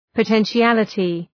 Προφορά
{pə,tenʃı’ælətı}